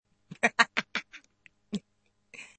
Descarga de Sonidos mp3 Gratis: risa 4.